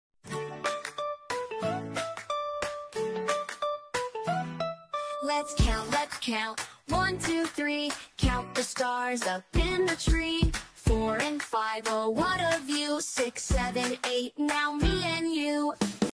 + A fun and cheerful song for kids to learn Numbers while singing and dancing.